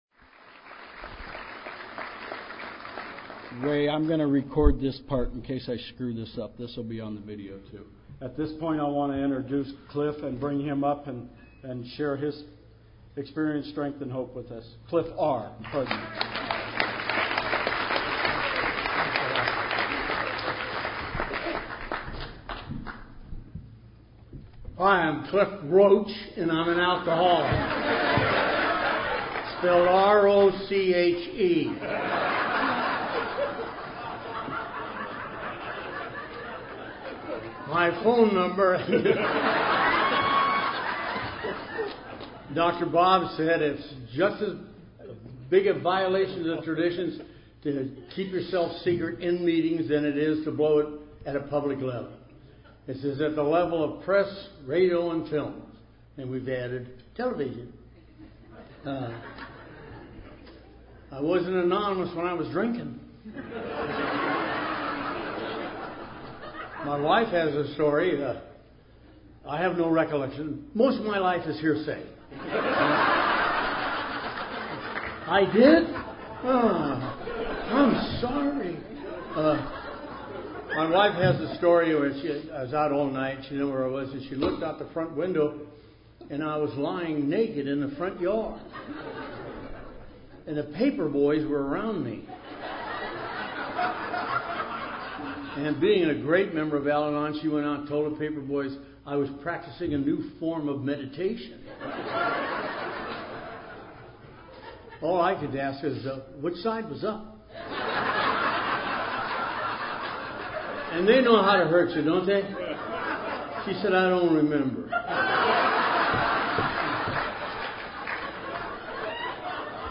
Santa Clara Valley 2012